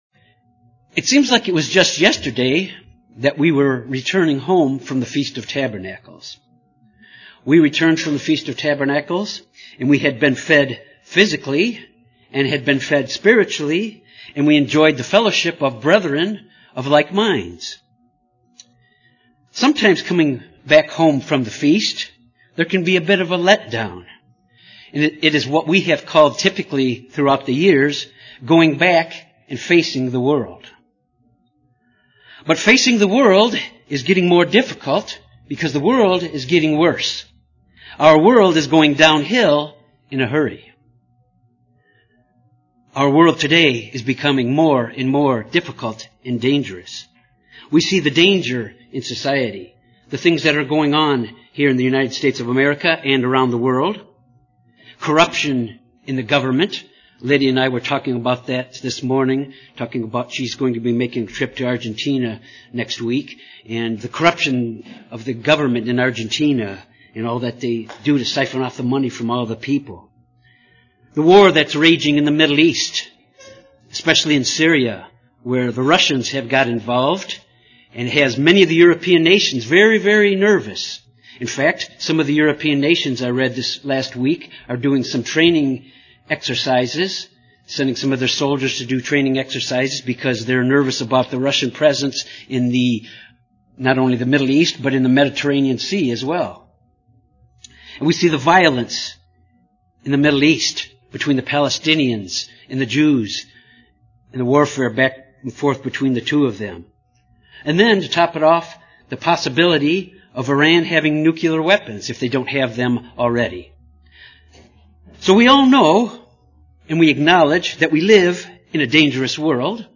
Sermons
Given in Little Rock, AR